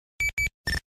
FX - NEXTEL.wav